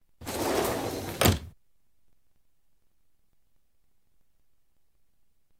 doorsclosed.wav